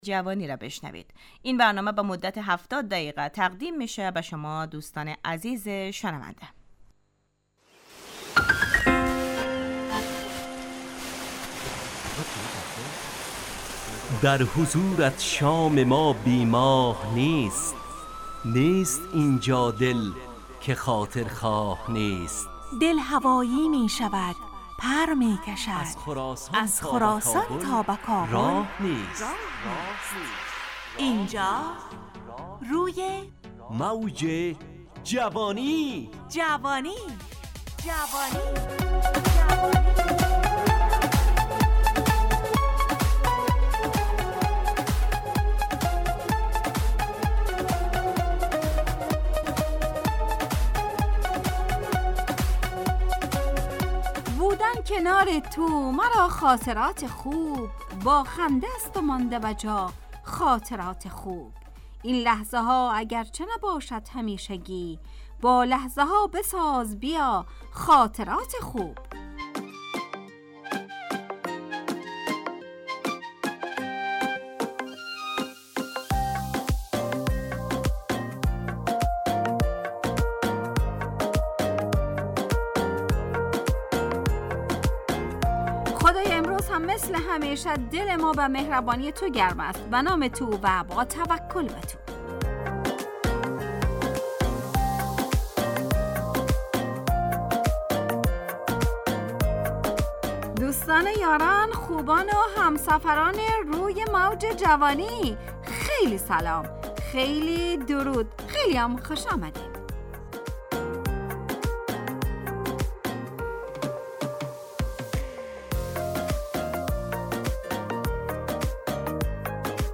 همراه با ترانه و موسیقی مدت برنامه 70 دقیقه . بحث محوری این هفته (خوب و بد)